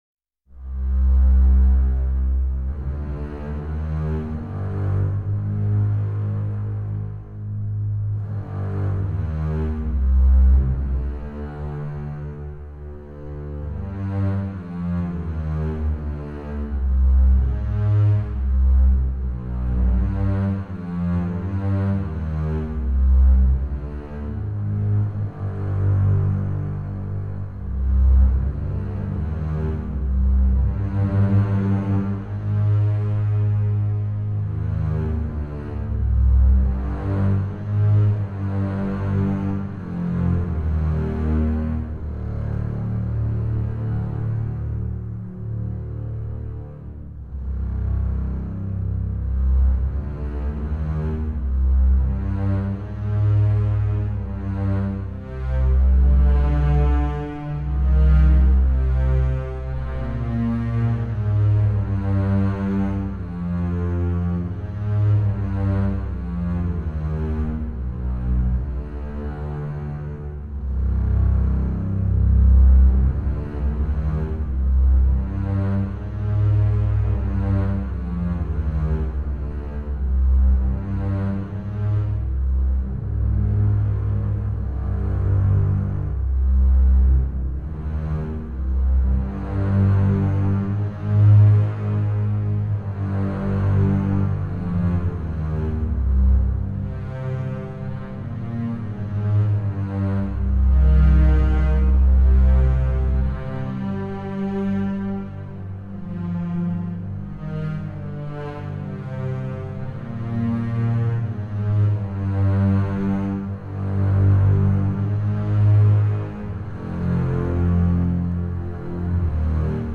string orchestra
trumpets
transverse flutes
percussion
clarinet
orchestral work in 7 pieces (21:15)